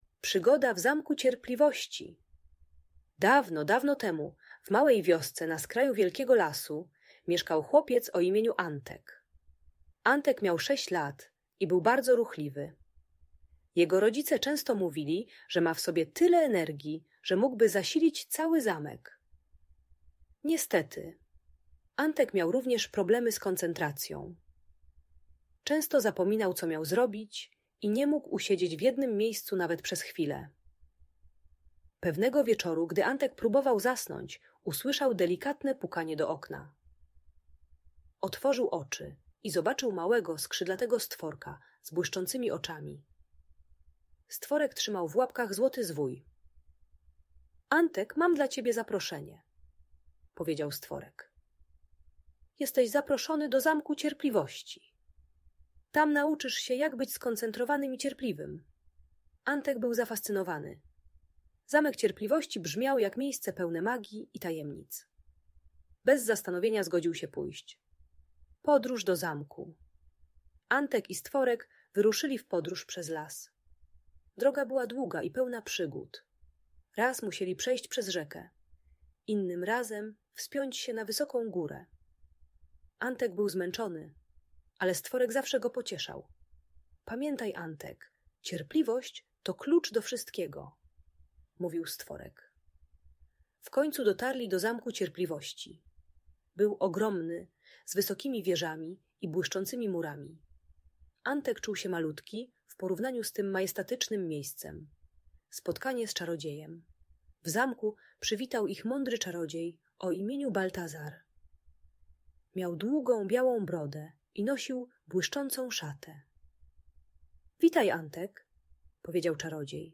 Przygoda Antka w Zamku Cierpliwości - Opowieść - Audiobajka dla dzieci